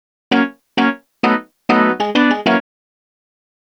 Swinging 60s 5 Organ-F#.wav